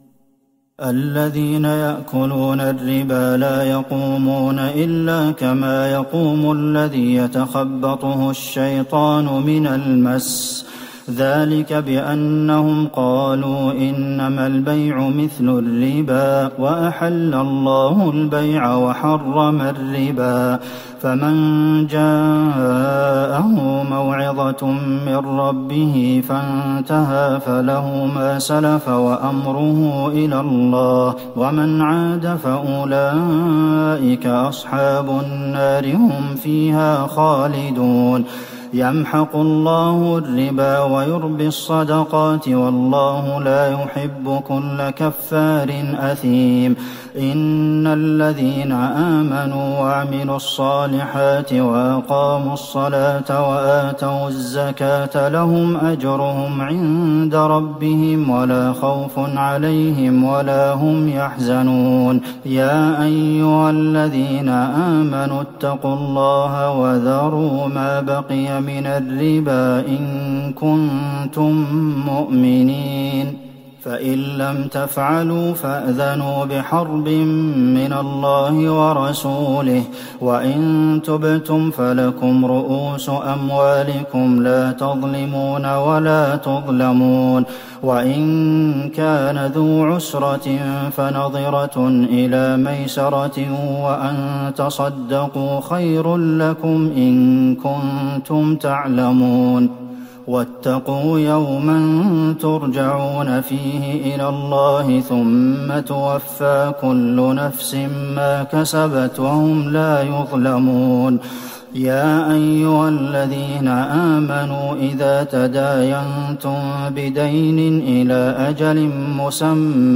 ليلة ٤ رمضان ١٤٤١هـ من سورة البقرة { ٢٧٥-٢٨٦ } وآل عمران { ١-٤١} > تراويح الحرم النبوي عام 1441 🕌 > التراويح - تلاوات الحرمين